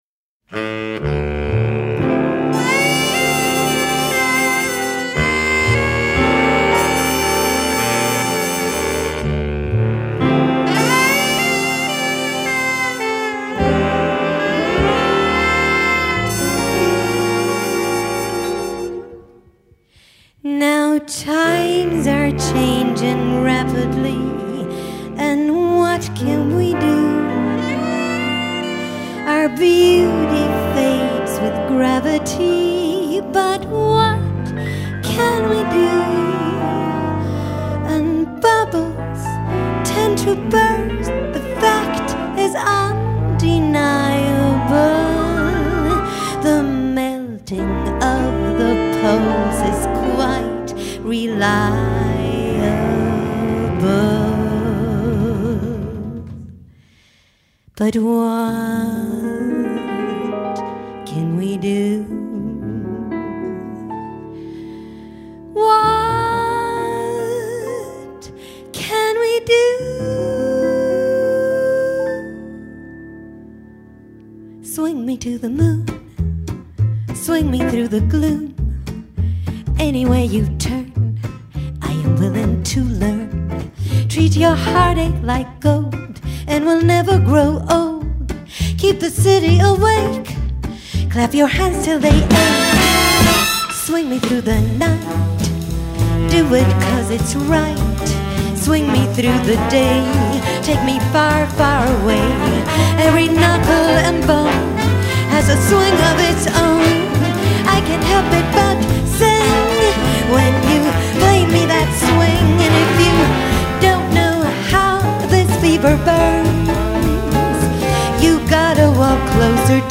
Toneart C Stil og taktart Swing
Nyttårskonsert 27.01.2024
09-A-Swing-Of-Its-Own-Live.mp3